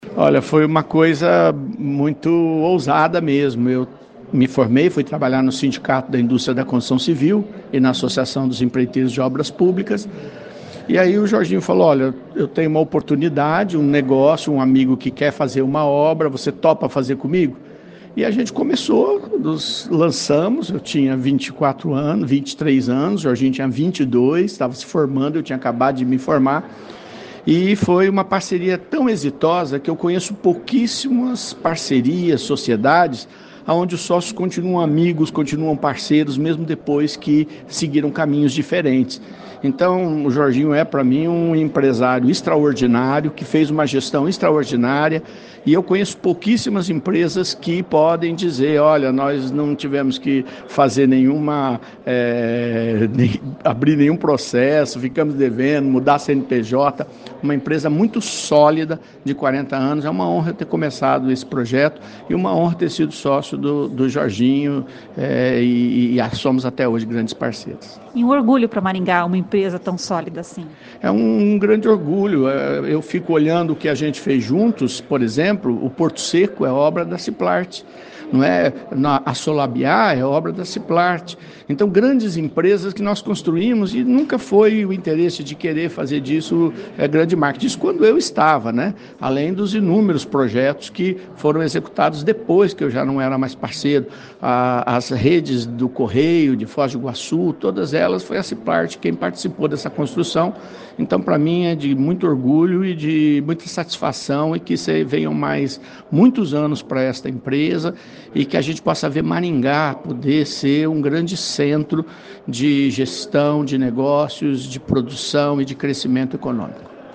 A entrevista foi realizada no estúdio móvel instalado na Construtora Ciplart, dentro do projeto CBN nas Empresas.